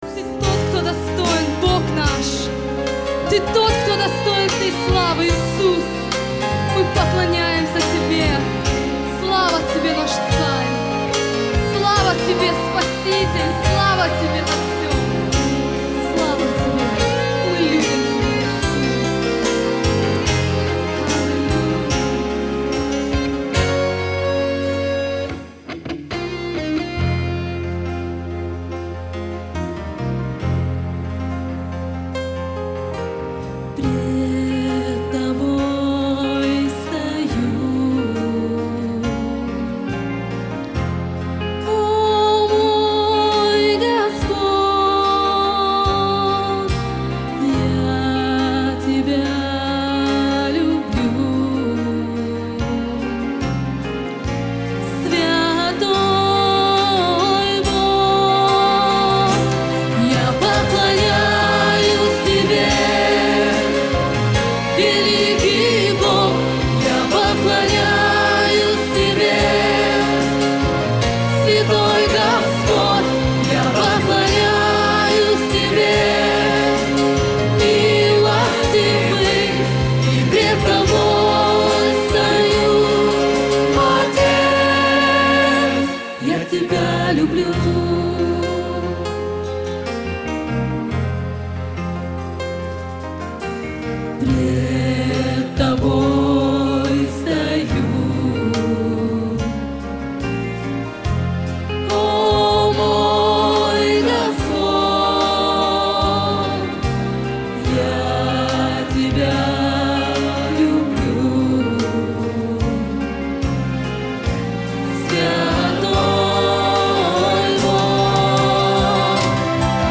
1308 просмотров 983 прослушивания 53 скачивания BPM: 70